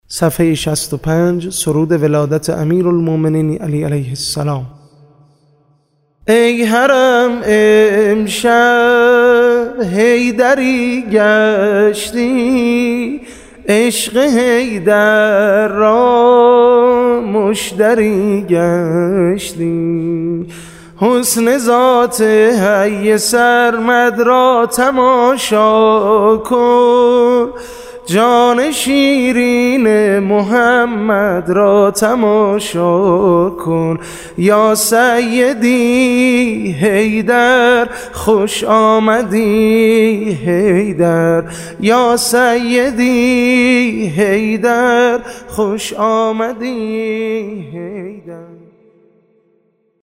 صوت مولودی ولادت حضرت علی(ع) به همراه متن - (ای حـرم امشب حیــدری گشتی)